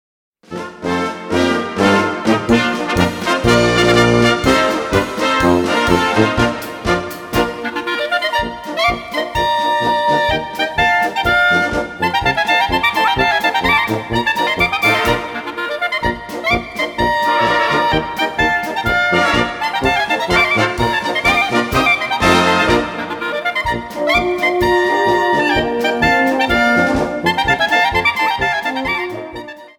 Gattung: für 2 Klarinetten in B oder Klarinette in Es und B
2:57 Minuten Besetzung: Blasorchester PDF